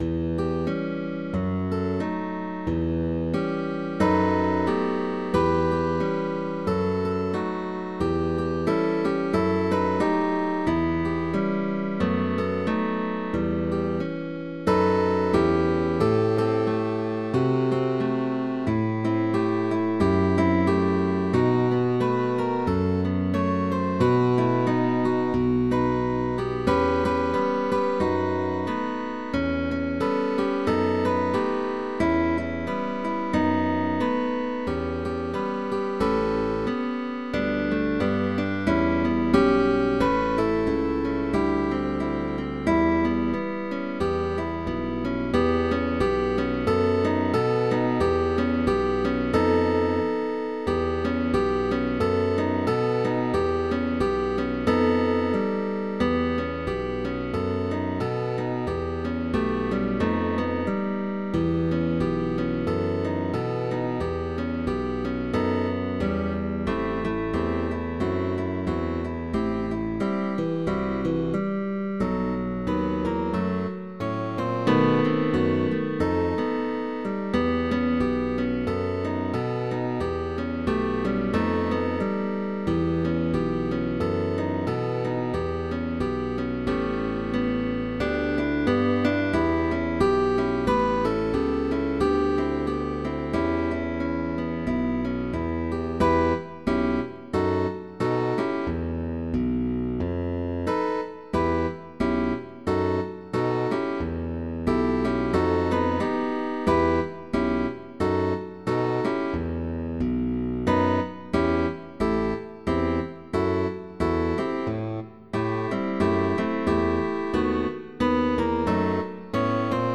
Guitar quartet sheetmusic.
GUITAR QUARTET